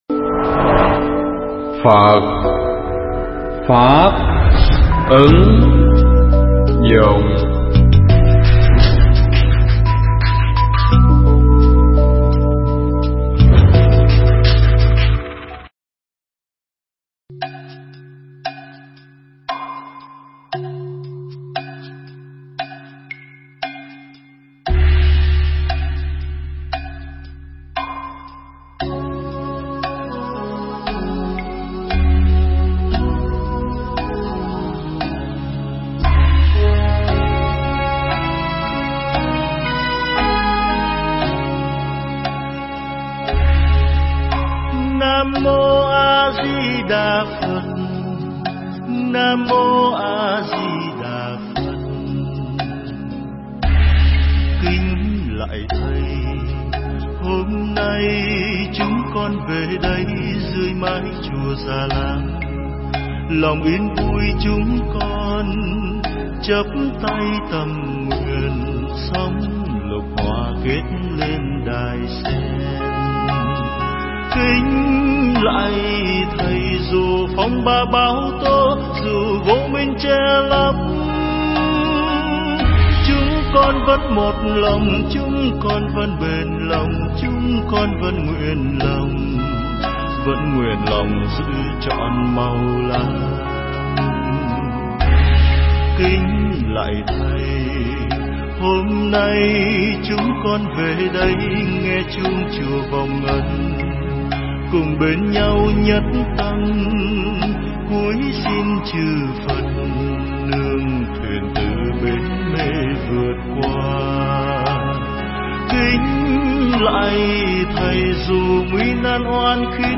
giảng tại chùa Ấn Quang (243 Sư Vạn Hạnh,Quận 10 ), và được phụ đề tiếng Anh